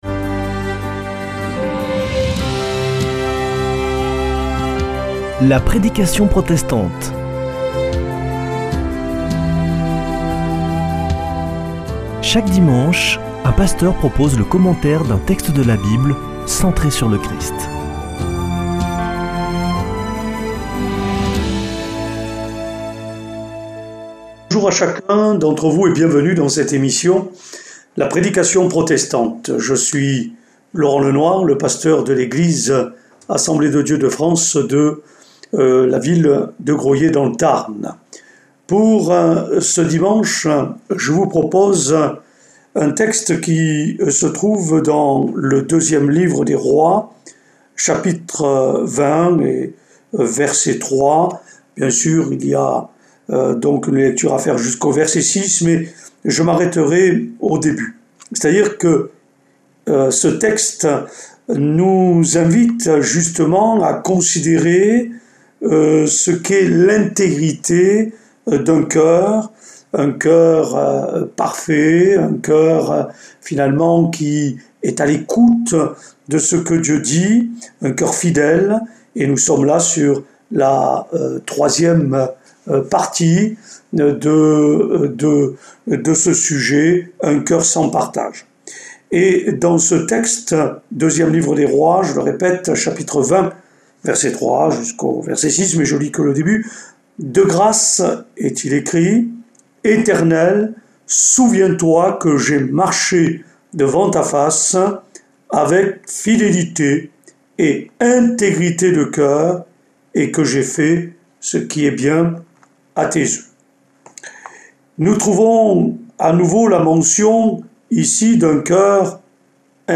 Accueil \ Emissions \ Foi \ Formation \ La prédication protestante \ Un coeur exemplaire 3ème partie : "un coeur parfait."